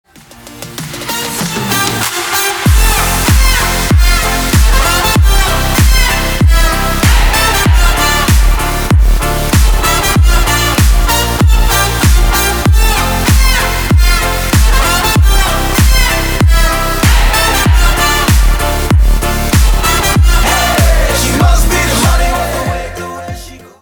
поп
громкие
dance
Танцевальный рингтон